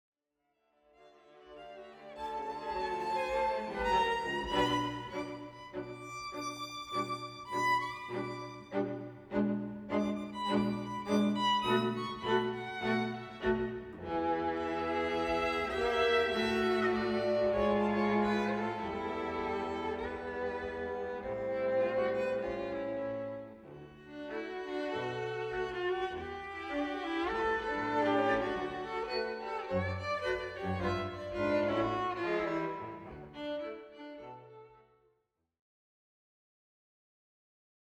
Jazz
En stråkkvartett från Göteborg